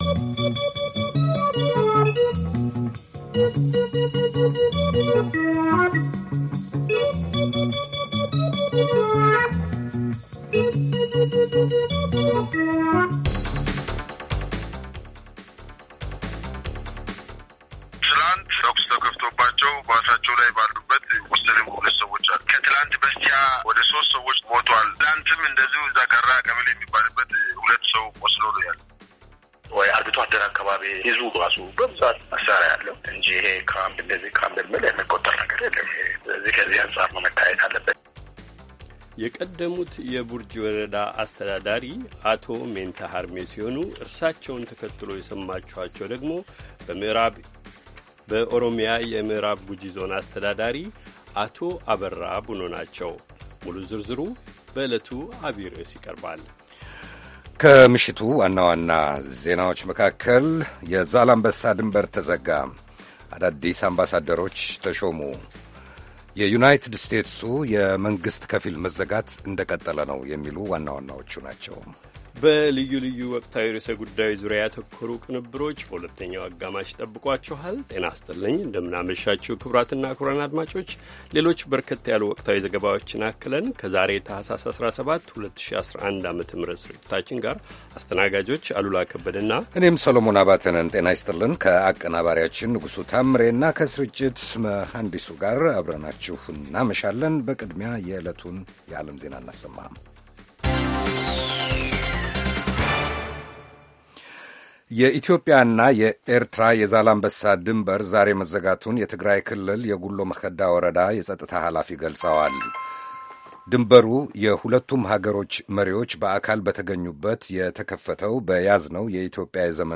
ቪኦኤ በየዕለቱ ከምሽቱ 3 ሰዓት በኢትዮጵያ አቆጣጠር ጀምሮ በአማርኛ፣ በአጭር ሞገድ 22፣ 25 እና 31 ሜትር ባንድ የ60 ደቂቃ ሥርጭቱ ዜና፣ አበይት ዜናዎች ትንታኔና ሌሎችም ወቅታዊ መረጃዎችን የያዙ ፕሮግራሞች ያስተላልፋል። ረቡዕ፡- ዴሞክራሲ በተግባር፣ ሴቶችና ቤተሰብ፣ አሜሪካና ሕዝቧ፣ ኢትዮጵያዊያን ባሜሪካ